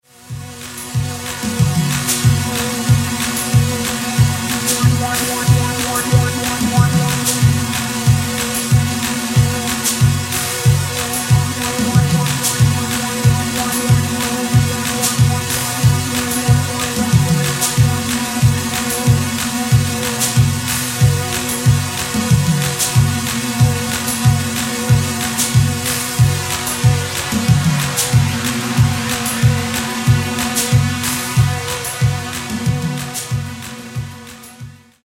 Genere: Disco | Electro Funky